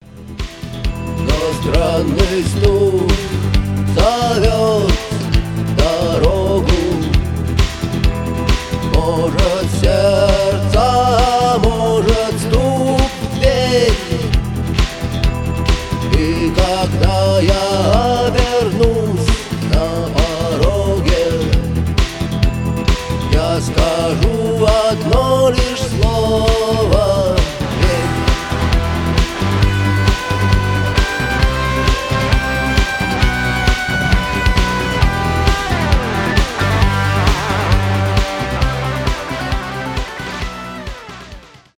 new wave , рок
пост-панк